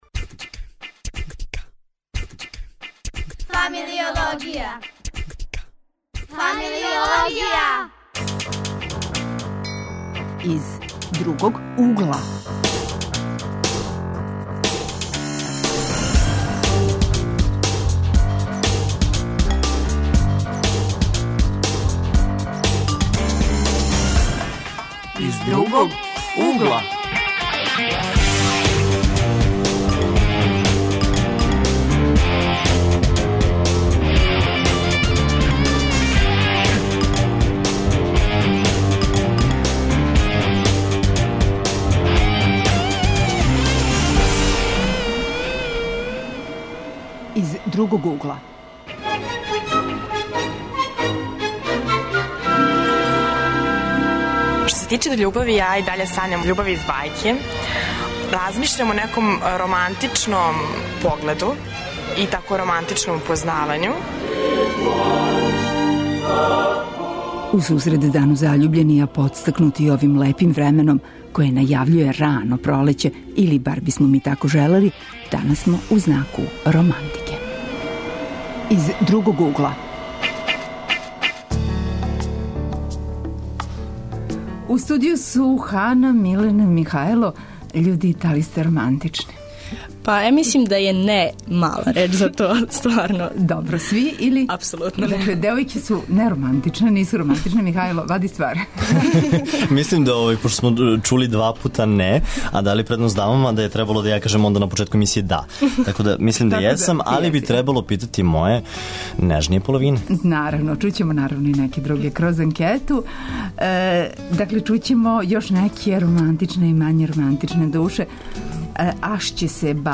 Гости су нам средњошколци и студенти који се на енциклопедијски начин баве романтичним пољупцима.